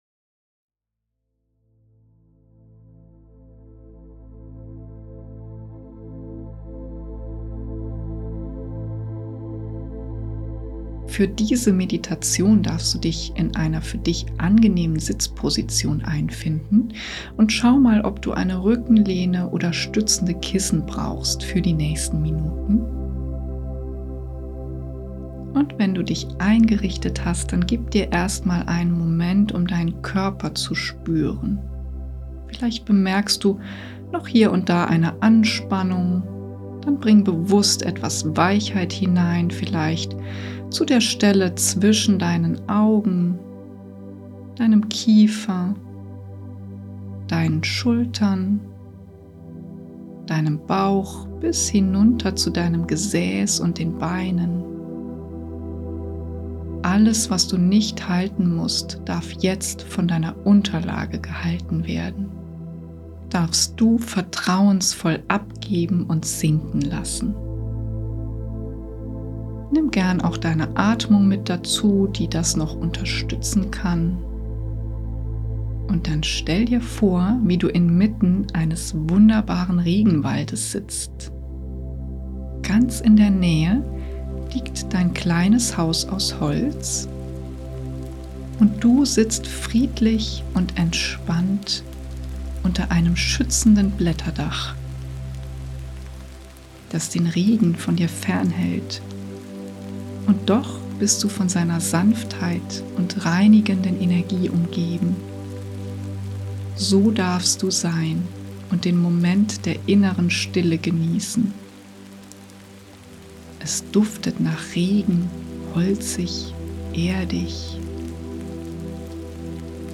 Jede Meditation ist einzigartig und ganz speziell auf die jeweilige Energie des entsprechenden Seelenöls abgestimmt, mit passender Musik und Sound unterlegt und produziert.
Hochwertige Audio-Qualität mit sanften Klängen, die deine Entspannung vertiefen
Die Magie entfaltet sich in der Frequenz und im harmonischen Zusammenspiel von Seelenölen, Stimme und Klang.
Jede Meditation wurde eigens komponiert und gestaltet – mit einer einzigartigen Kombination aus Musik, Tönen und Klangelementen – abgestimmt auf eines dieser 11 Seelenöle von Young Living: